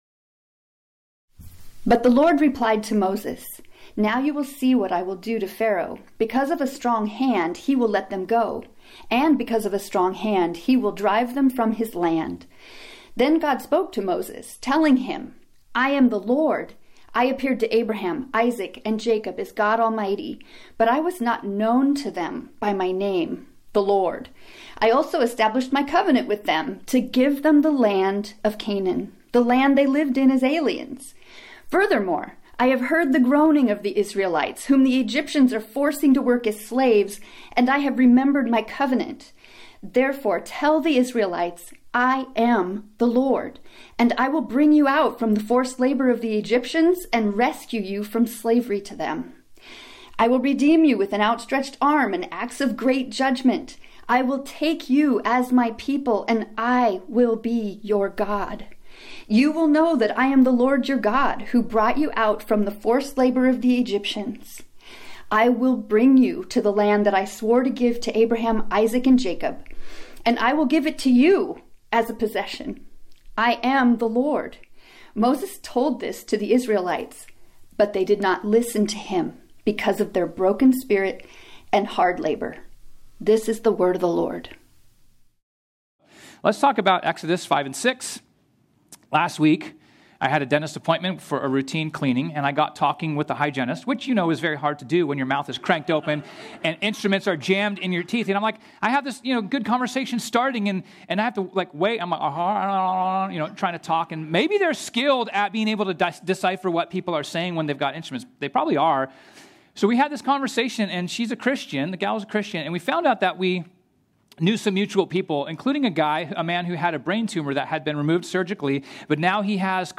This sermon was originally preached on Sunday, February 2, 2025.